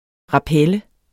Udtale [ ʁɑˈpεlə ]